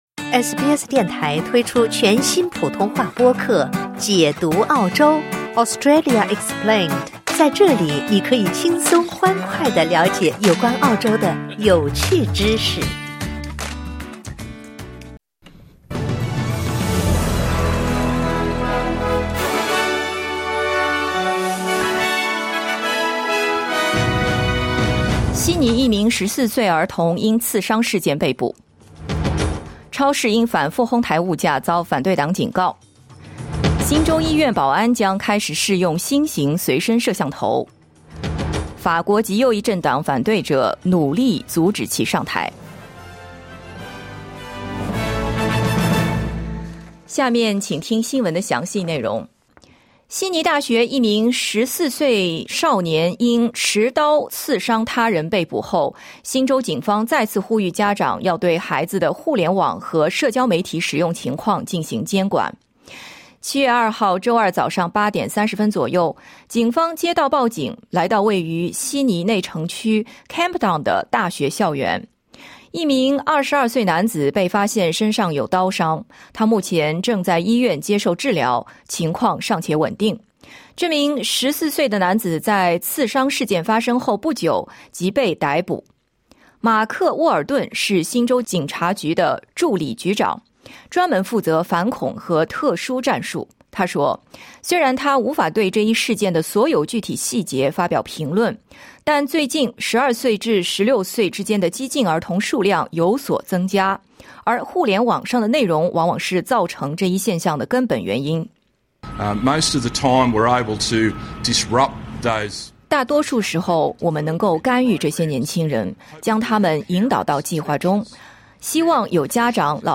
SBS早新闻（2024年7月3日）